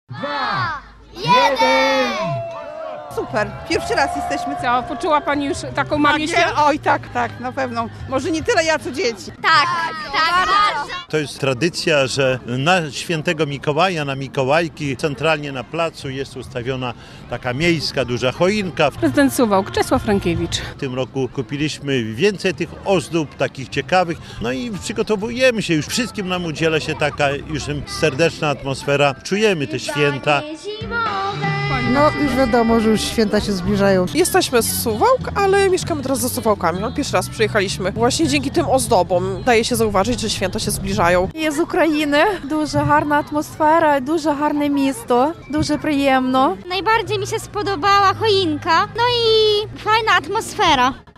Miejska choinka w Suwałkach - relacja